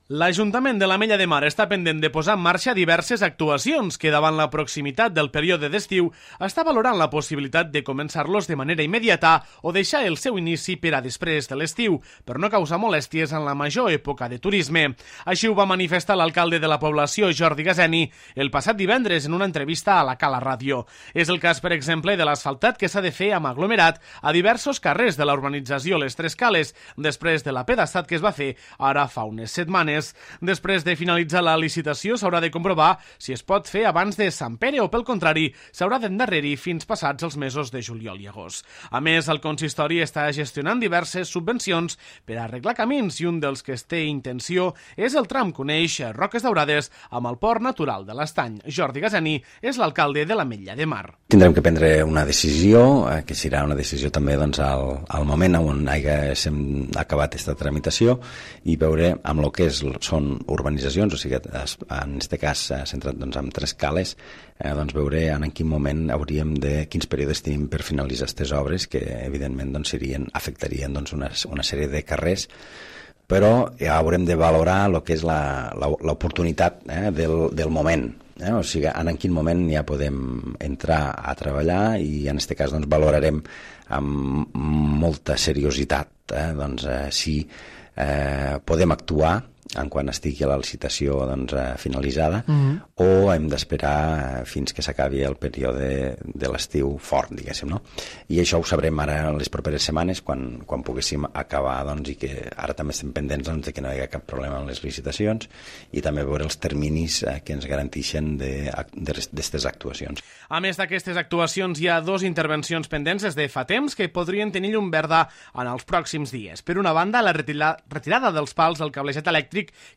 Així ho va manifestar l'alcalde de la població, Jordi Gaseni, el passat divendres en una entrevista a La Cala Ràdio.